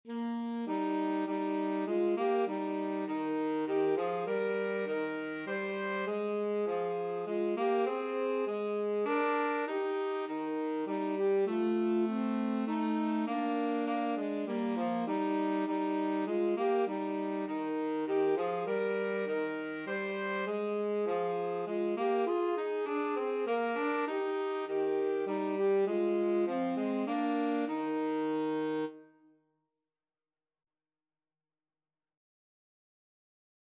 Alto SaxophoneTenor Saxophone
3/4 (View more 3/4 Music)
Christmas (View more Christmas Alto-Tenor-Sax Duet Music)